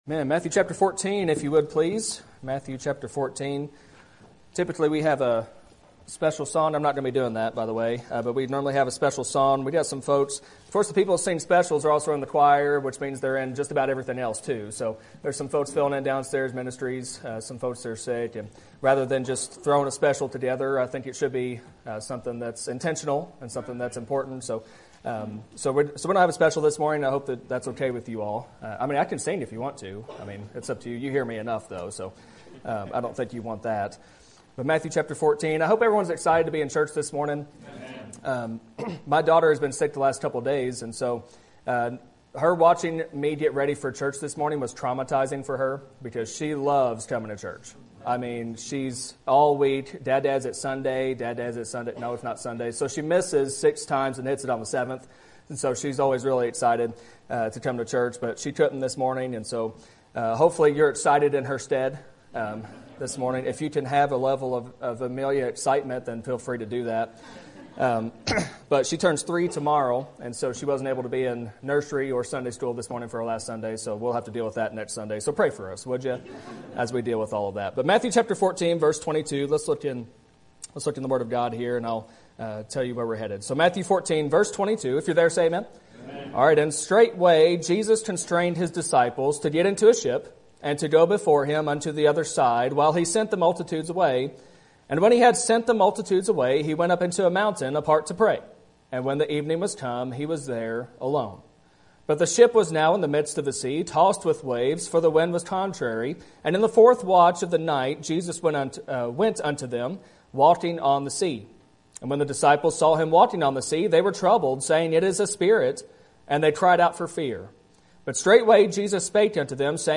Sermon Topic: General Sermon Type: Service Sermon Audio: Sermon download: Download (19.85 MB) Sermon Tags: Matthew Faith Peter Jesus